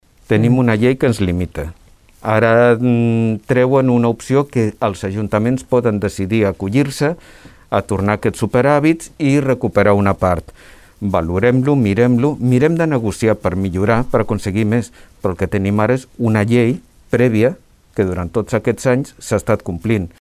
Justament el regidor Rafa Delgado, del PSC, va afirmar en declaracions a Ràdio Tordera que l’opció és “complir les lleis”.